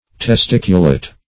Search Result for " testiculate" : The Collaborative International Dictionary of English v.0.48: Testiculate \Tes*tic"u*late\, a. [NL. testiculatus.]
testiculate.mp3